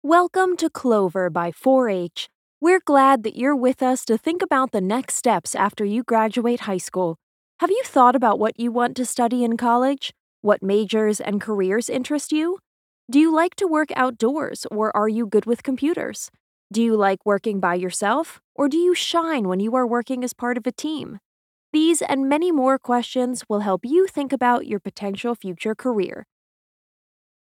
hell, fein, zart, sehr variabel
Jung (18-30)
Audioguide, Doku, Narrative, Presentation